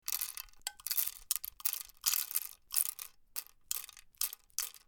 Звуки кофемолки
Почувствуй кофейные зерна в кофемолке